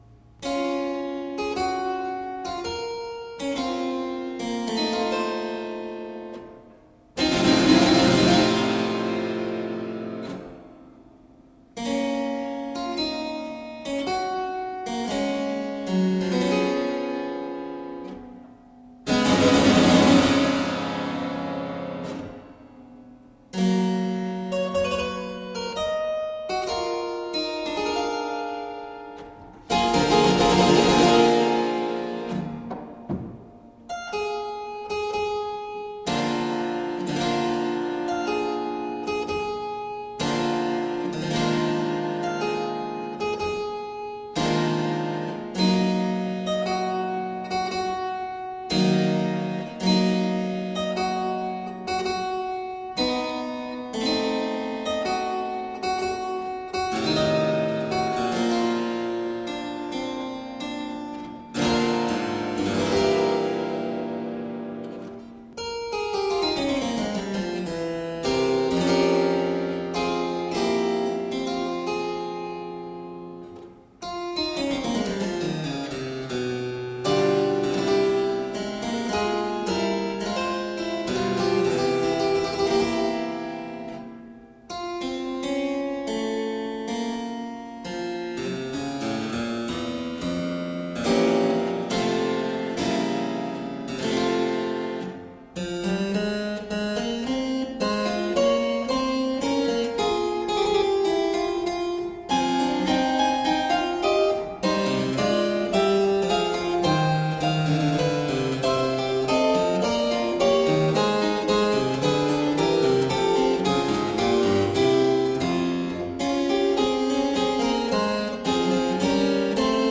Heartfelt music for harpsichord.
solo harpsichord works
Classical, Baroque, Instrumental
Harpsichord